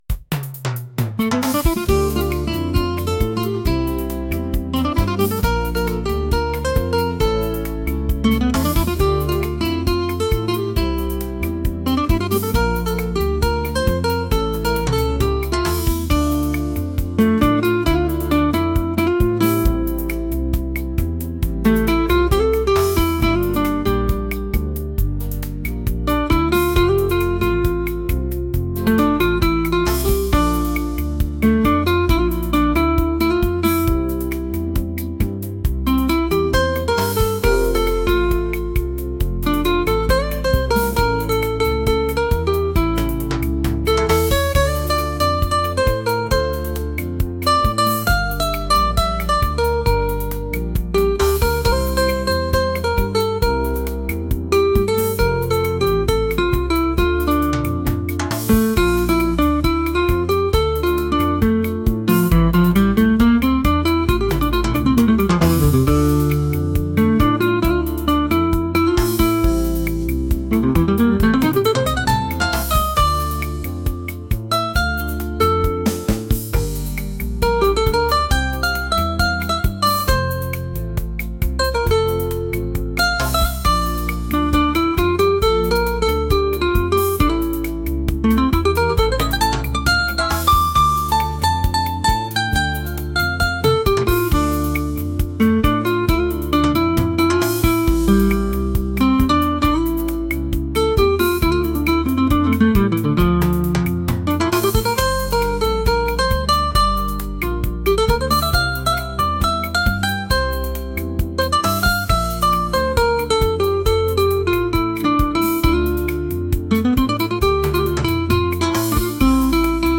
latin | romantic